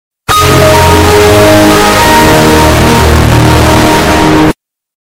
Windows Sound Effect Download: Instant Soundboard Button